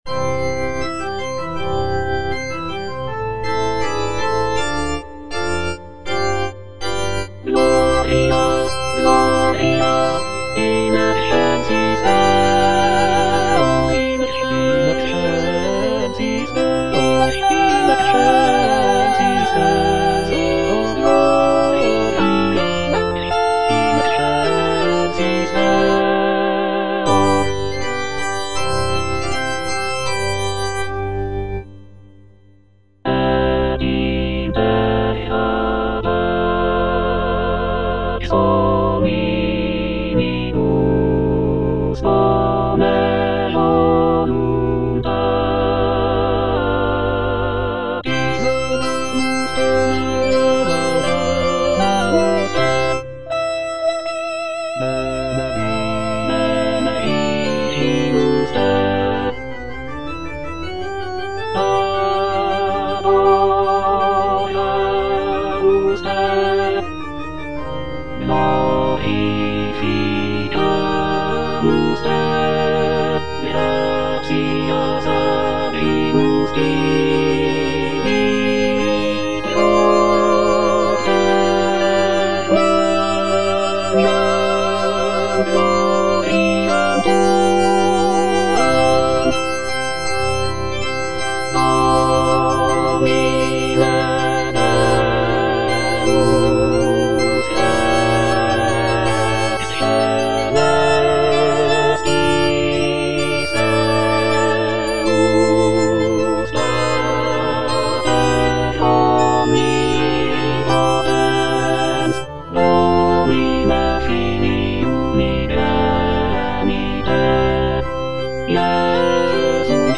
(All voices)
a sacred choral work